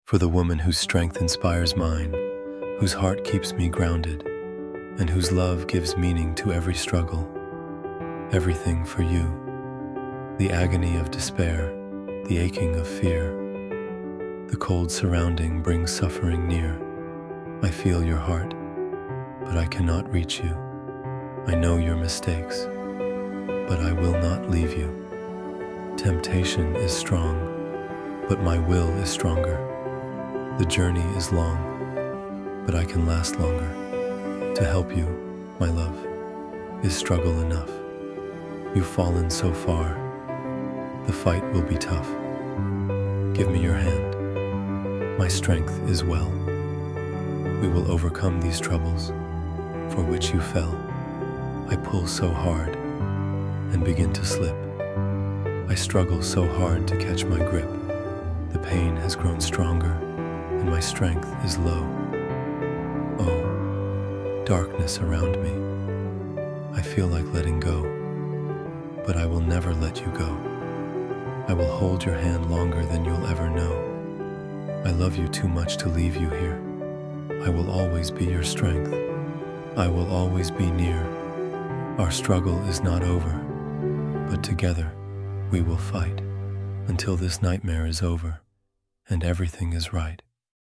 Everything-for-You-–-Love-Poem-Spoken-Word-Deep-Love-Poems.mp3